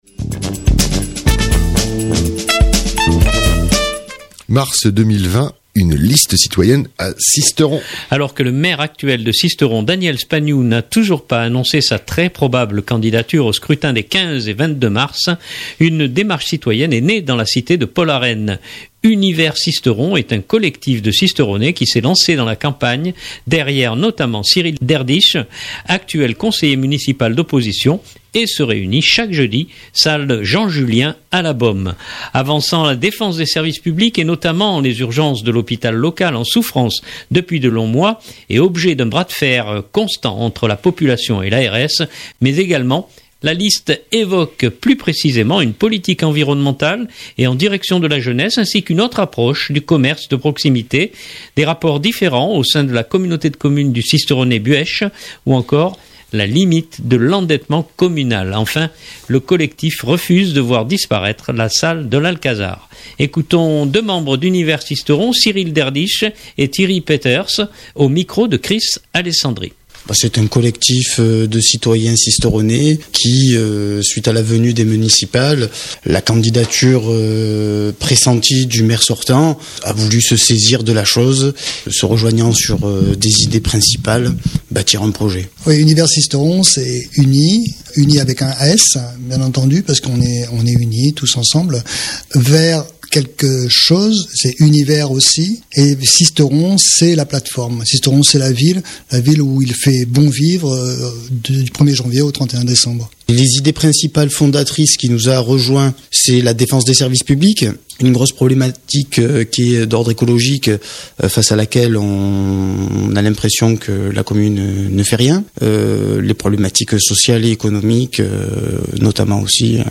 Écoutons deux membres d’UnisverSisteron